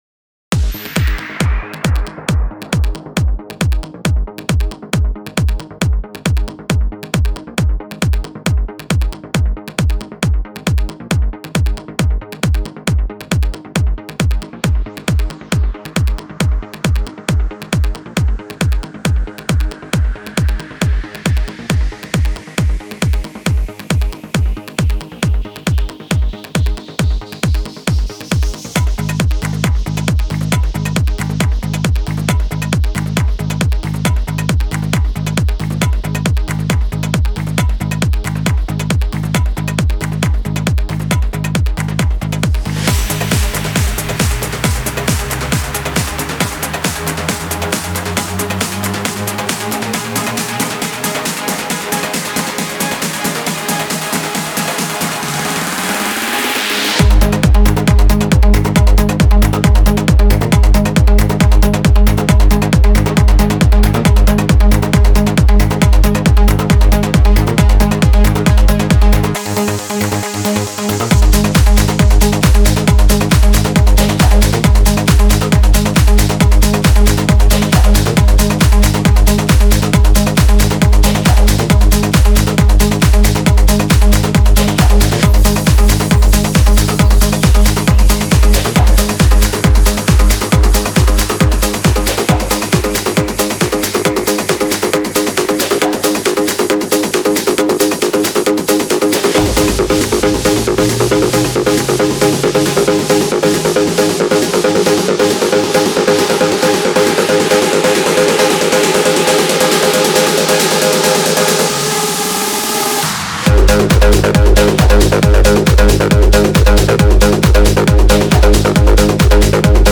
Стиль: Tech Trance